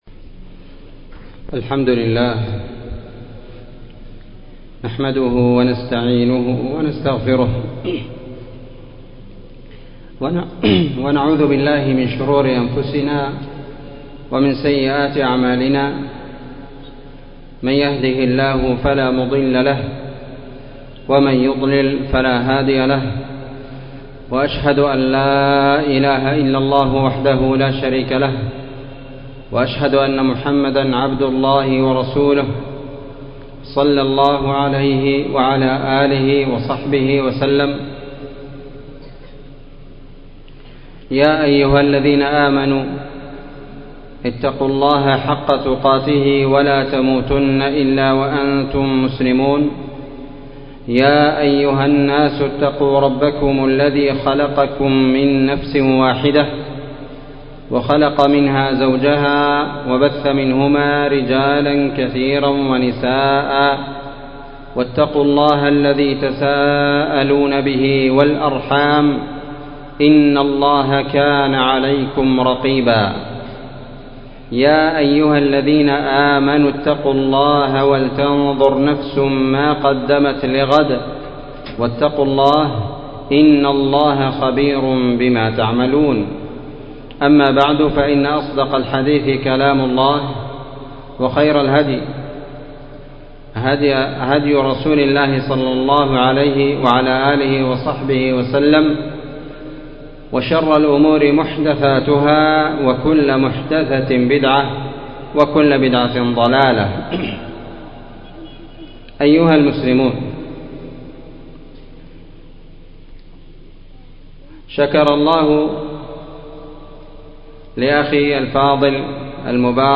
الوسواس أسبابه وعلاجه محاضرة بين مغرب وعشاء ليلة السبت ٢٥ رجب ١٤٤٣